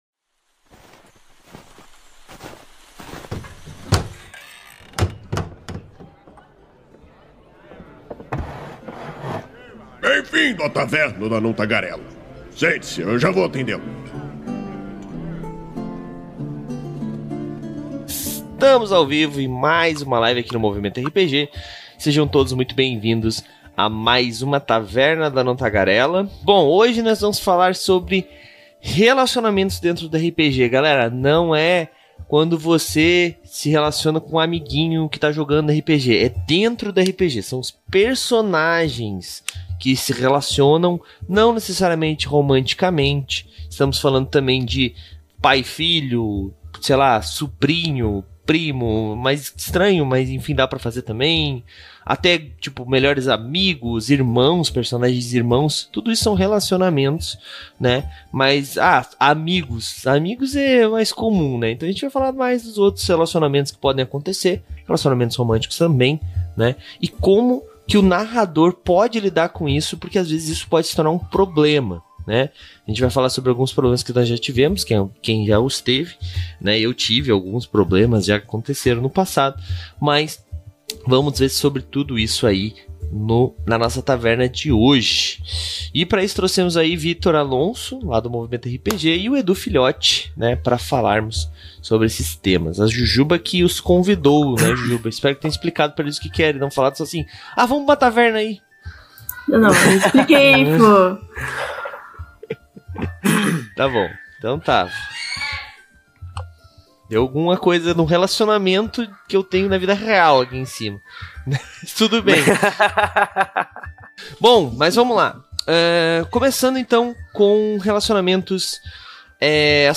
Venha saber mais sobre como relacionamentos internos podem influenciar sua jogatina positivamente e como evitar que essas relações interfiram na vida fora da sua mesa. A Taverna do Anão Tagarela é uma iniciativa do site Movimento RPG, que vai ao ar ao vivo na Twitch toda a segunda-feira e posteriormente é convertida em Podcast. Com isso, pedimos que todos, inclusive vocês ouvintes, participem e nos mandem suas sugestões de temas para que por fim levemos ao ar em forma de debate.